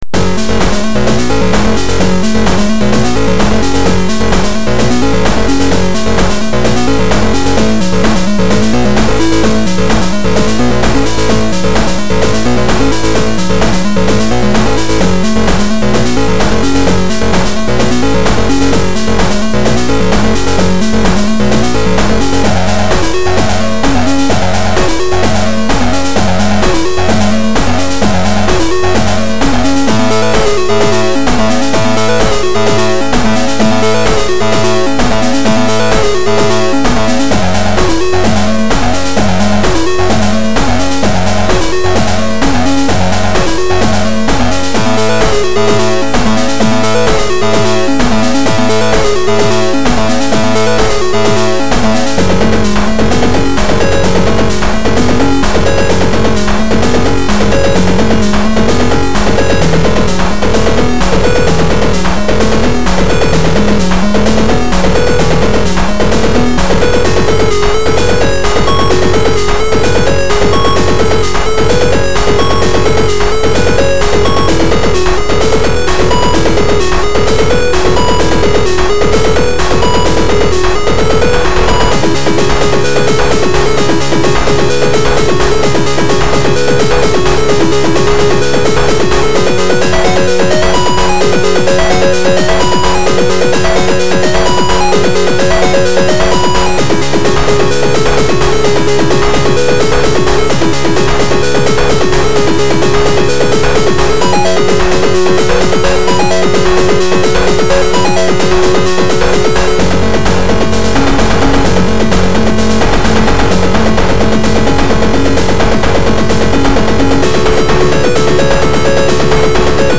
This is probably my favorite chiptune I've written in a long time.
I tried writing something a little heavier, more metal-esque.